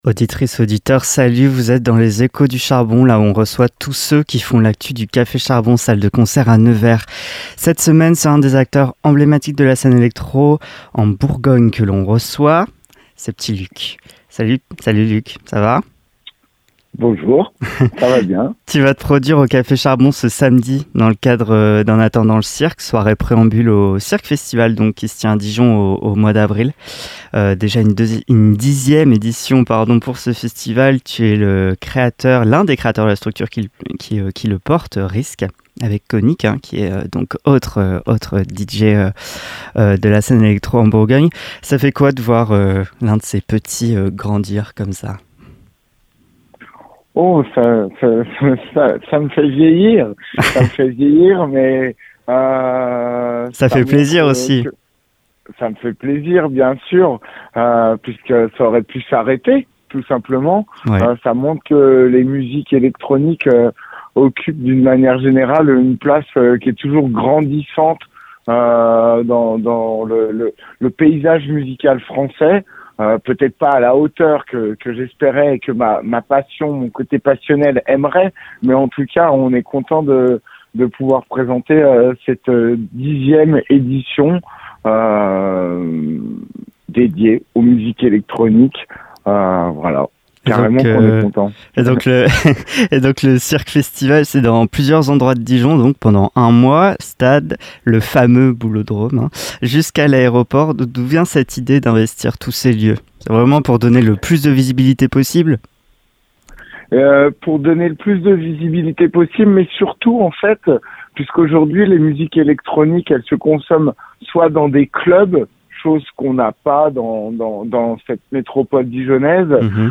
Les Échos du Café Charbon - Interview